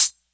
Closed Hats
Hat (15).wav